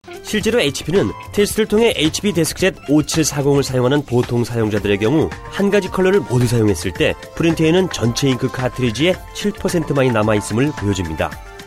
Professioneller koreanischer Sprecher für TV / Rundfunk / Industrie.
Sprechprobe: Werbung (Muttersprache):
Professionell korean voice over artist